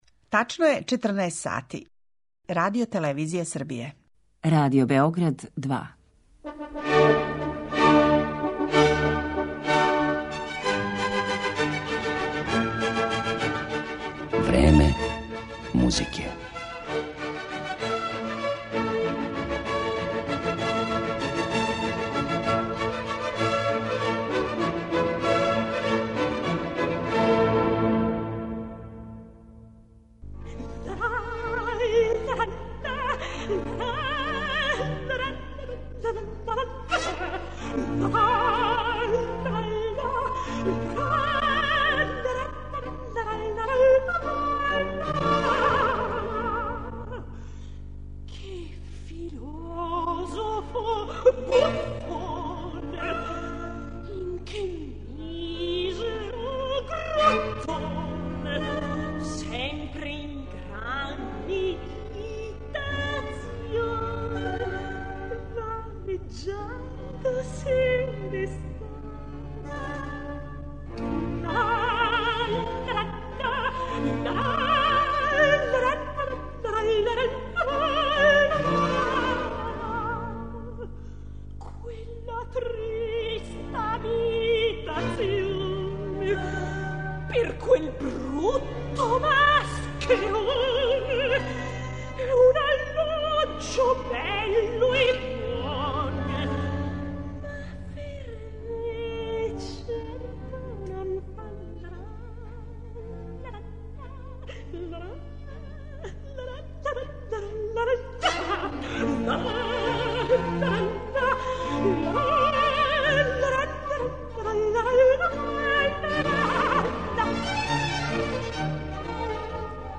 Рани послеподневни сат уз класичну музику Радио Београда 2 посветили смо композитору чије је име далеко познатије од његових дела, а потрудићемо се и да разјаснимо неке чињенице које су утицале на рецепцију његове музике током два и по претходна века.